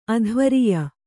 ♪ adhvariya